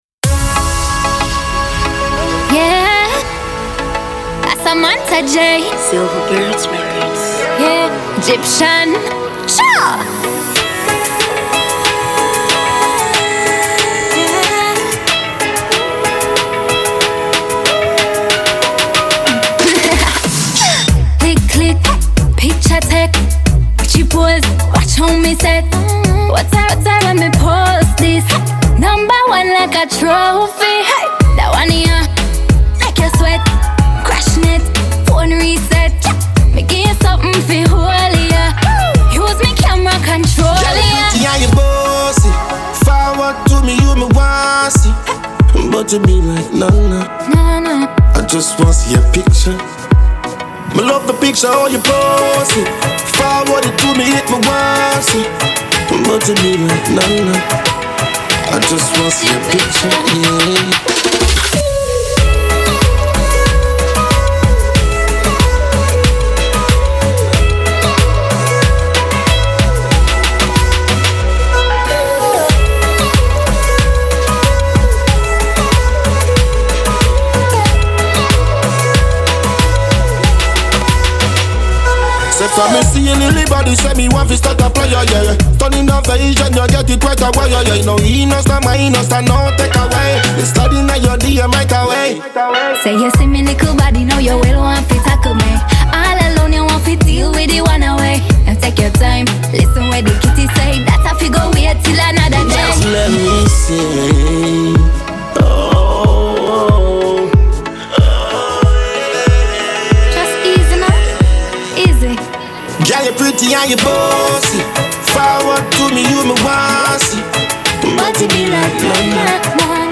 это яркая и запоминающаяся композиция в жанре регги-поп